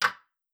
tick.wav